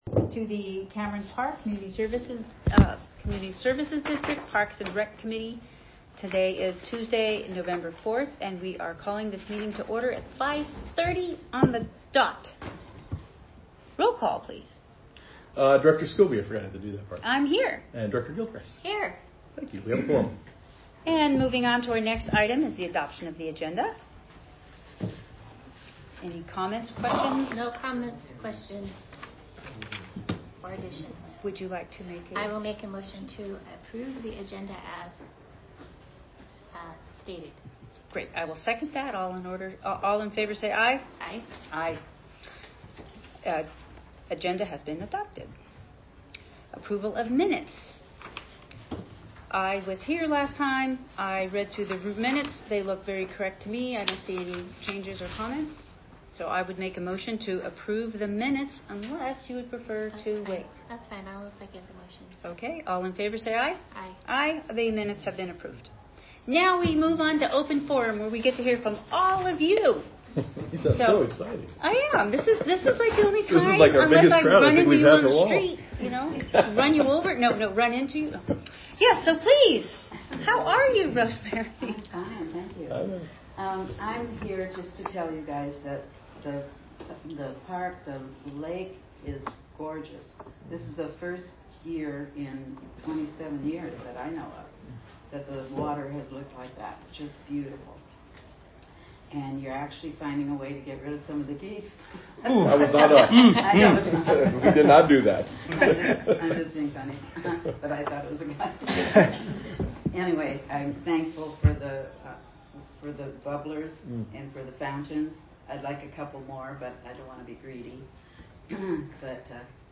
Parks & Recreation Committee Regular Meeting